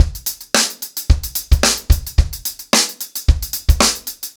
TupidCow-110BPM.29.wav